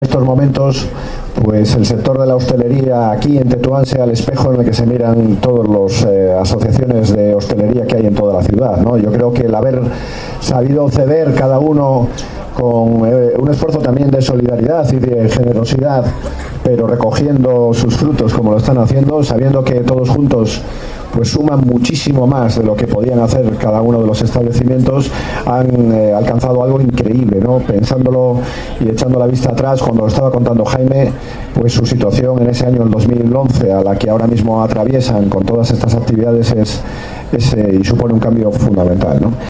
DE LA SERNA -DESCUBRIMIENTO ESTRELLA DAVID BUSTAMANTE TETU�N